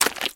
STEPS Swamp, Walk 19.wav